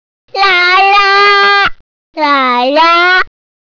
Wer nochmal mitraten möchte, kann sich ja zuerst die Tierstimmen anhören.